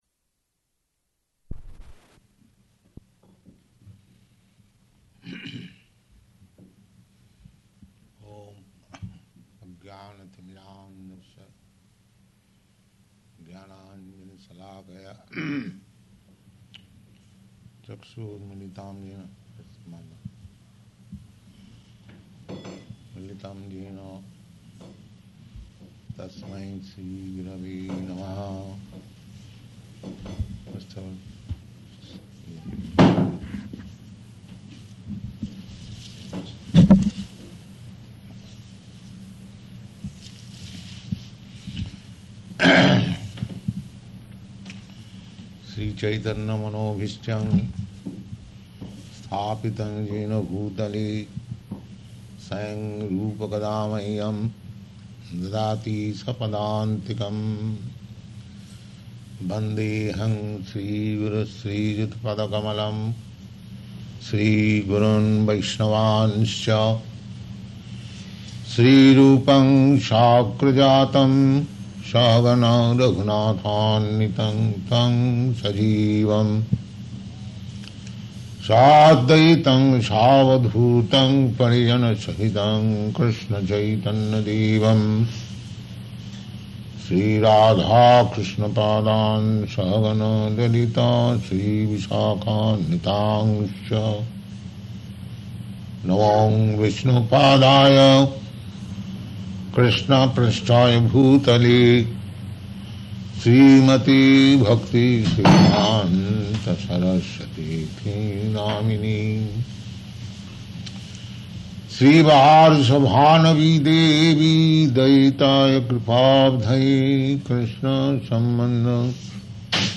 Lecture
Location: Hawaii